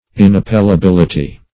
Search Result for " inappellability" : The Collaborative International Dictionary of English v.0.48: Inappellability \In`ap*pel`la*bil"i*ty\, n. The quality of being inappellable; finality.
inappellability.mp3